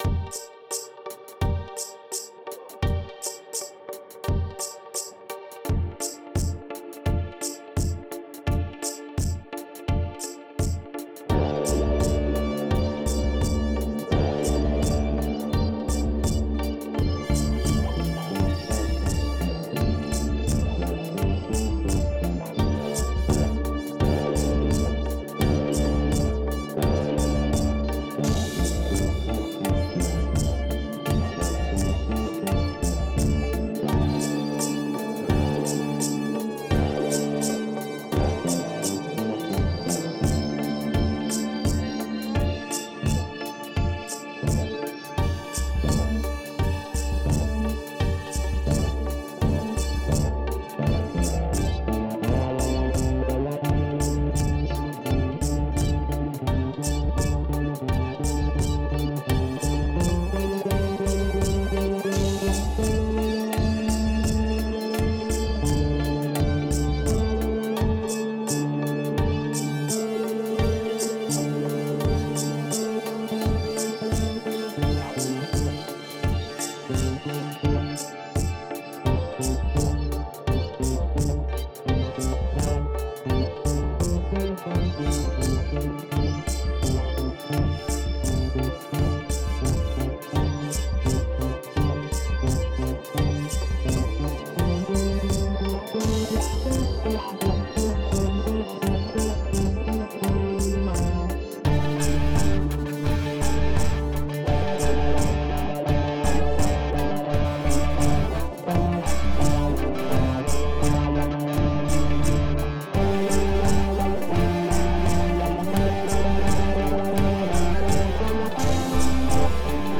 All I can really do is mainly root note and follow kick.
This is all improve, there is no editing for the most part. EQ on each channel, mainly for the filtering out the low frequencies. Bass track and its sub track does have compression since it is a bass.
Main instrument that goes the full length is the bass. Once again, one single take, no editing.